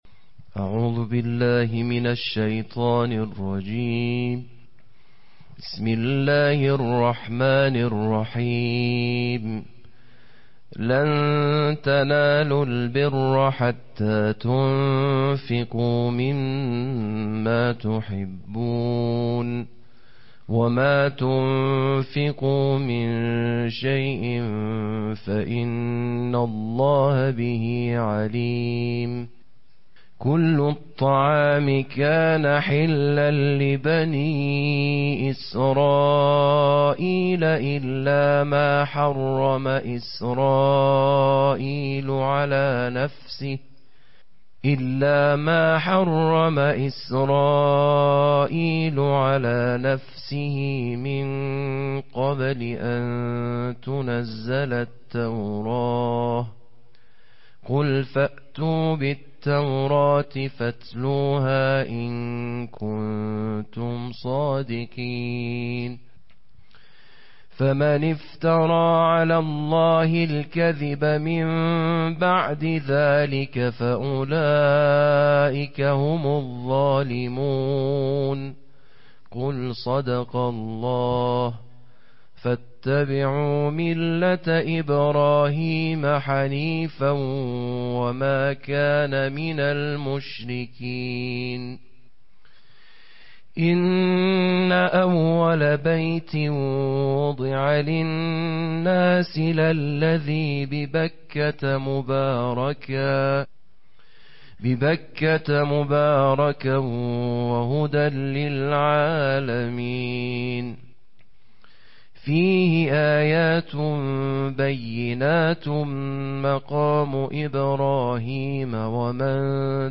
چوتھے پارے کی تلاوت بین الاقوامی قراء کی آواز میں + آڈیو